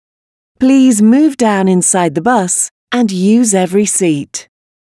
movedownthebus.mp3